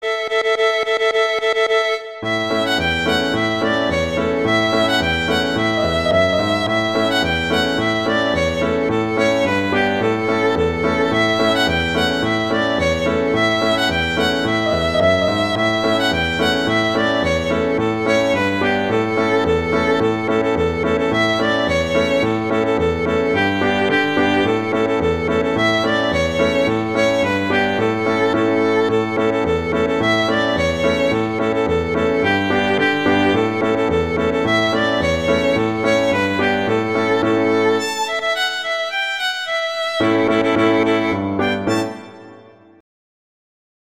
arrangements for violin and piano
traditional, children